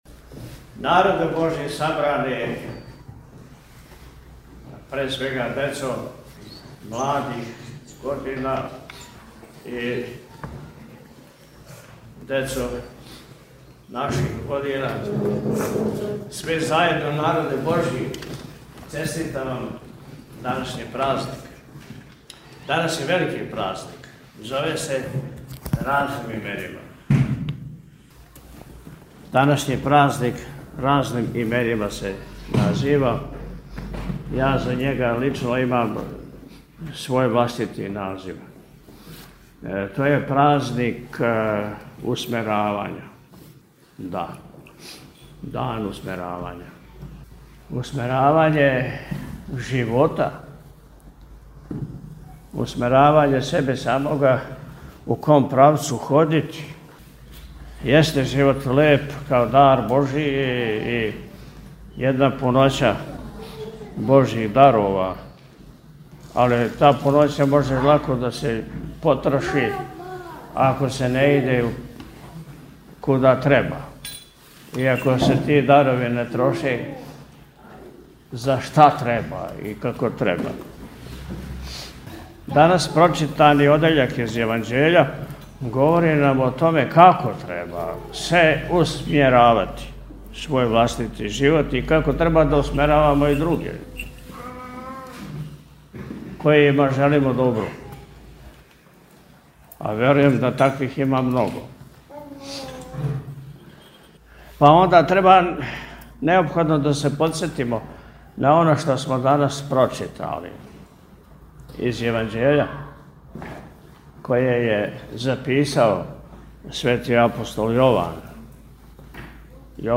Након отпуста Високопреосвећени је у пастирској беседи, између осталог, рекао: – Данашњи празник је дан усмеравања.